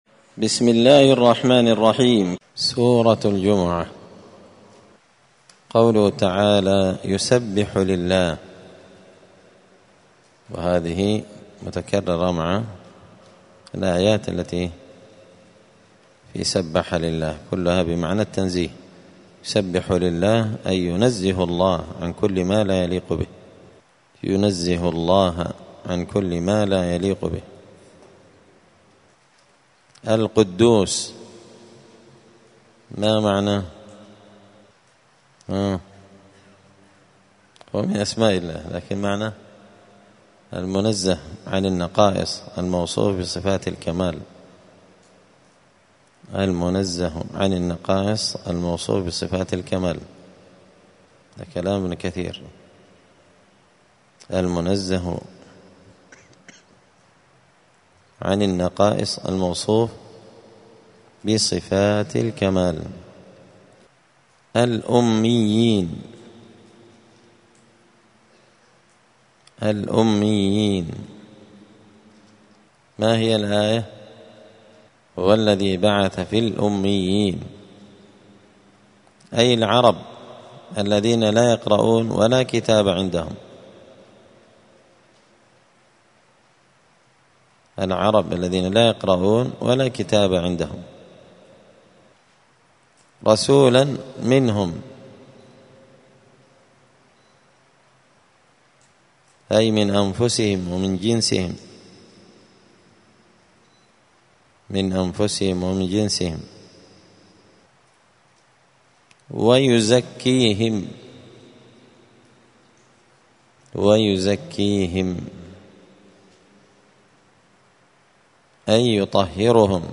الثلاثاء 3 شعبان 1445 هــــ | الدروس، دروس القران وعلومة، زبدة الأقوال في غريب كلام المتعال | شارك بتعليقك | 27 المشاهدات